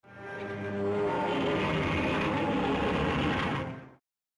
The roar of the Killer Whale.
Killer_Whale_Roar.ogg